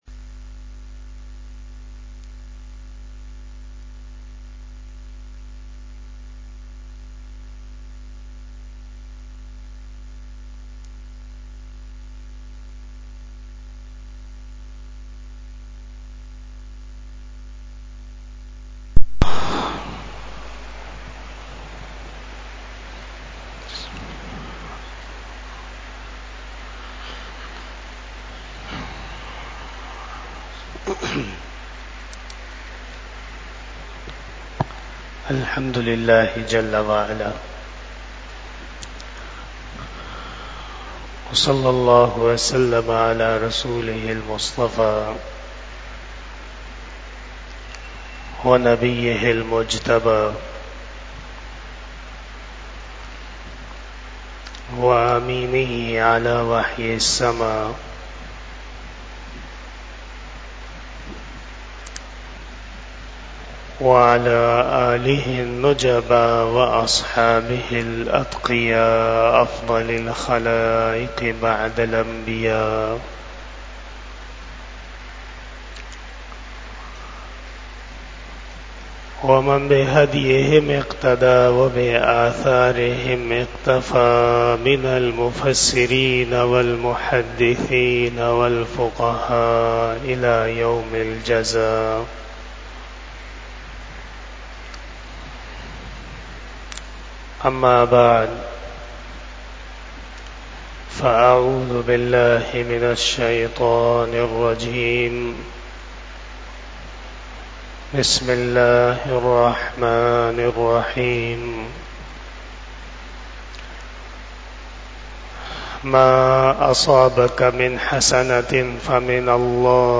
بیان جمعۃ المبارک 10 صفر المظفر ۱٤٤٦ھ بمطابق 16 اگست 2024ء